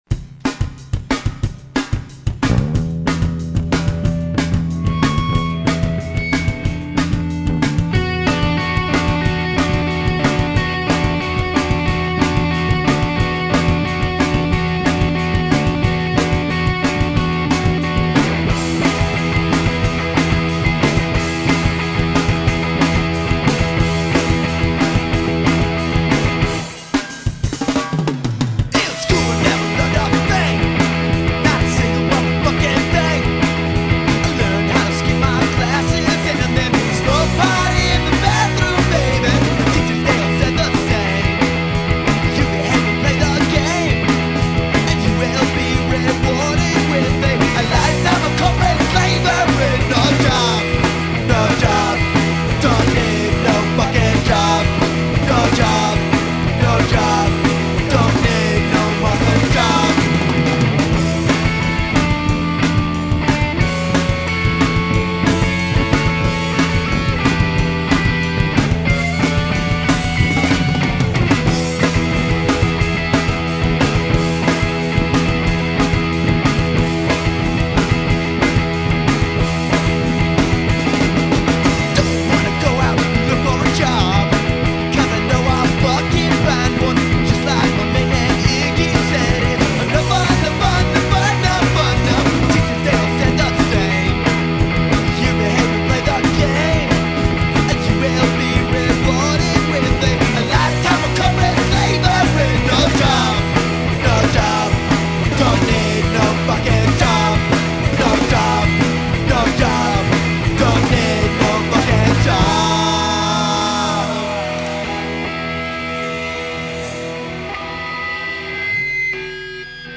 Garage punk band
garage rock See all items with this value
punk rock See all items with this value
vinyl record